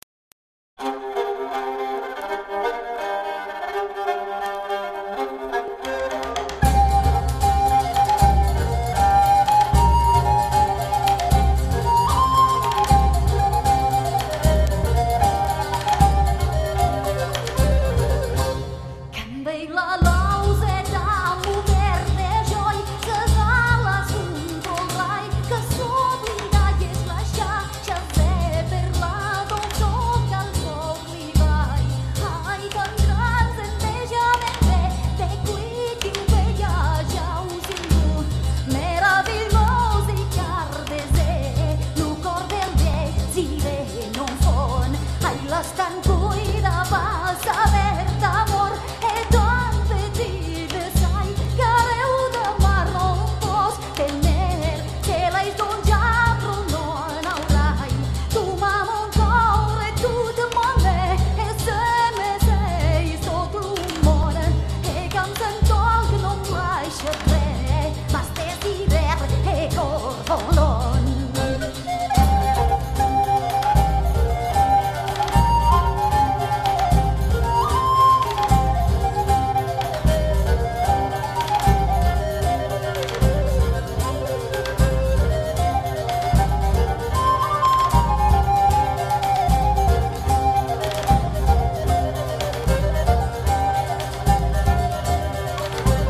medievale
Troubadours